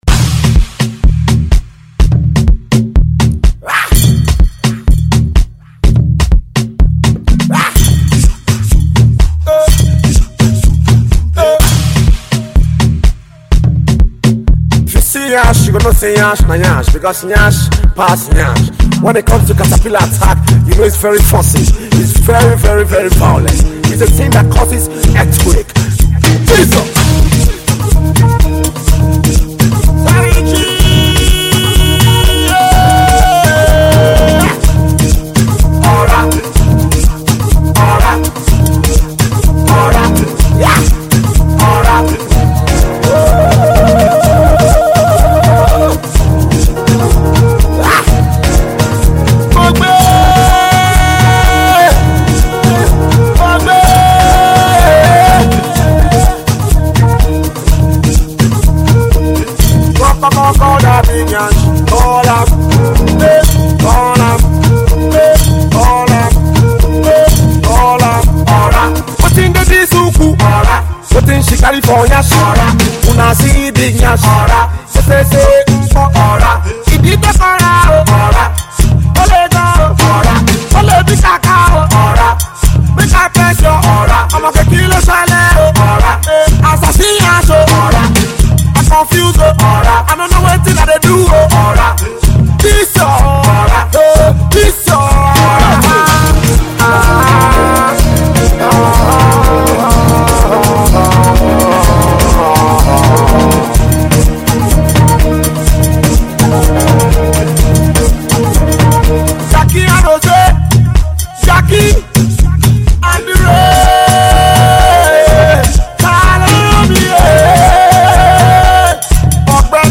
club hits